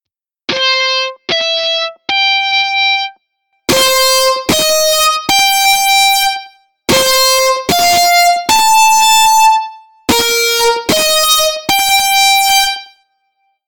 Processing at 3.5 seconds.
That’s Effect > Echo: 0.1, 0.2. > Effect > Distortion > Leveler at maximum > Leveler at maximum again.
I just thought it sounded a little better and mellower with that on my speakers.